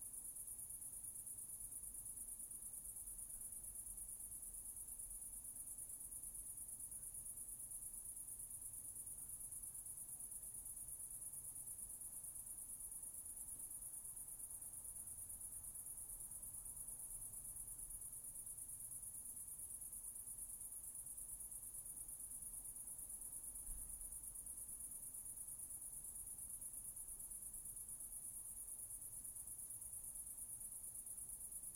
insectday_19.ogg